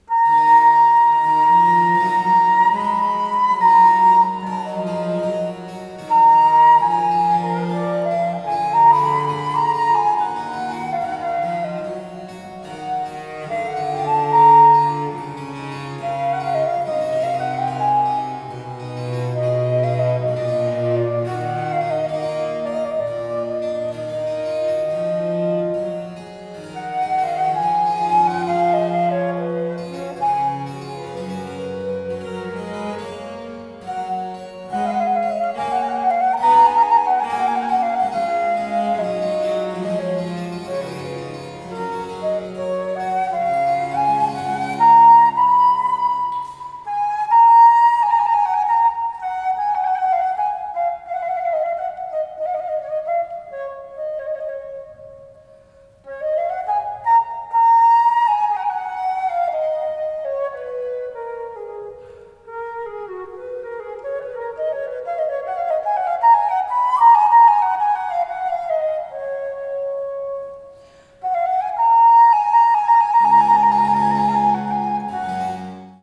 flet traverso oraz zespołu muzyki dawnej Musicarion
skrzypce barokowe
wiolonczela barokowa
klawesyn.